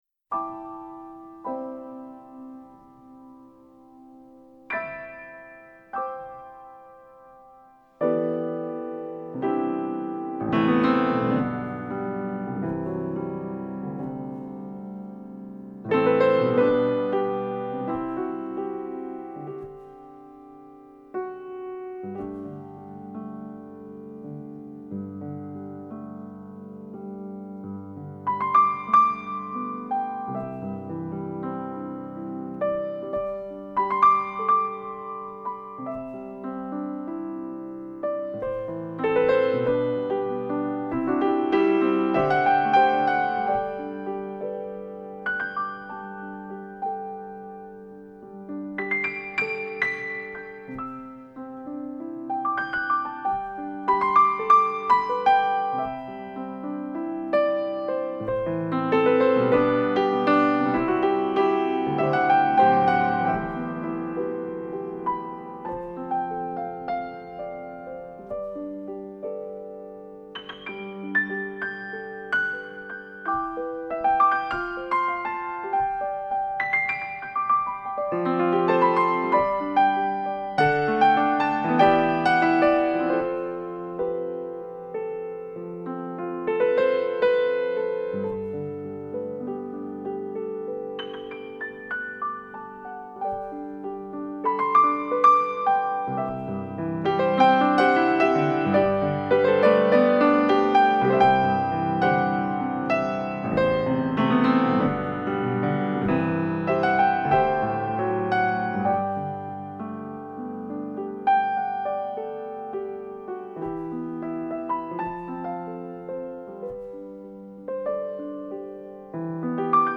名家钢琴
连续两年评选为Billboard杂志年度10大新世纪艺人
他的音乐听来丰富而轻盈，不同于极简派钢琴的制式化曲式、以及过度集中于钢琴中音带的狭隘，演奏时宽阔自在，聆赏之下处处惊喜。